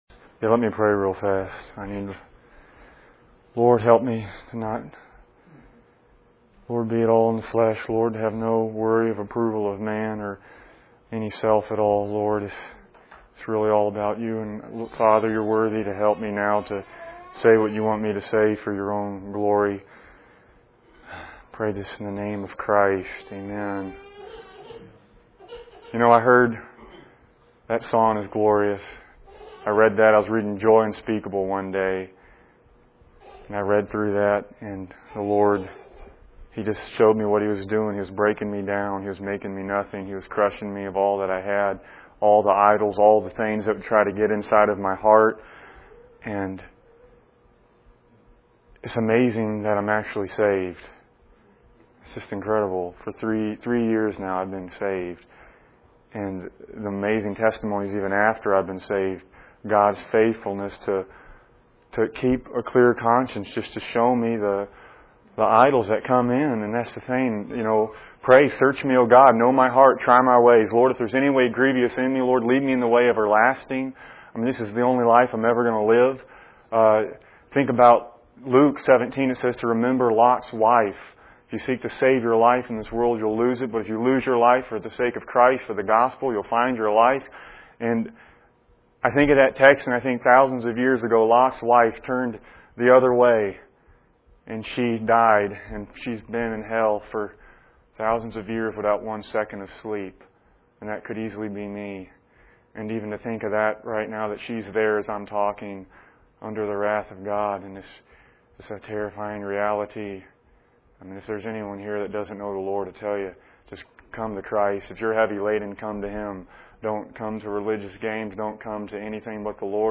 In this sermon, the speaker emphasizes the importance of seeking the Lord and sharing the gospel with others. He shares his personal testimony of being deceived for three years before being saved by God. The speaker highlights the need to put sin to death and live by the Spirit, as stated in Romans 8:13.